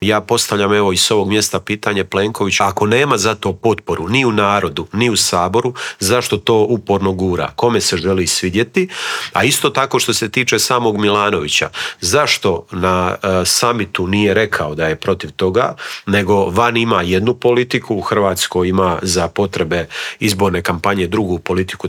U Intervjuu Media servisa gostovao je upravo Nikola Grmoja i podijelio s nama dojmove sa sjednice.